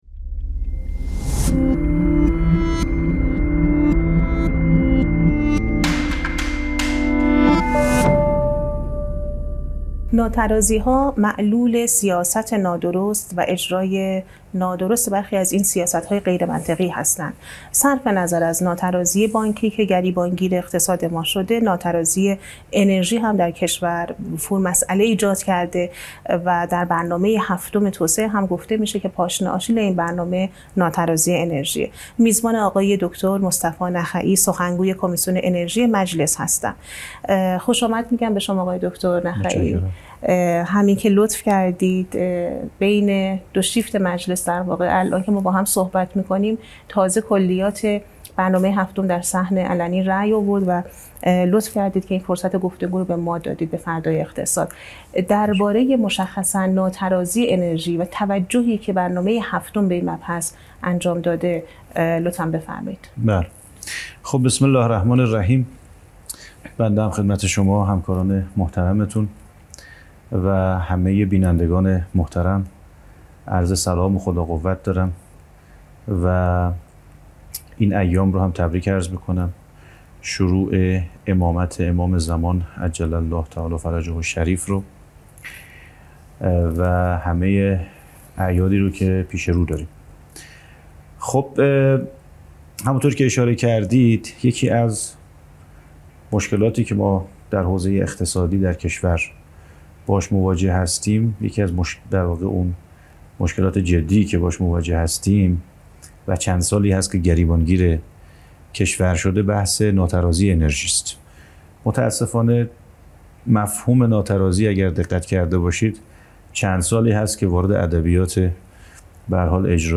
«فردای‌ اقتصاد» در گفت‌وگو با مصطفی نخعی، سخنگوی کمیسیون انرژی مجلس موضوع ناترازی انرژی در برنامه هفتم را بررسی کرده است.